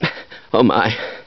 Download Half Life Oh My sound effect for free.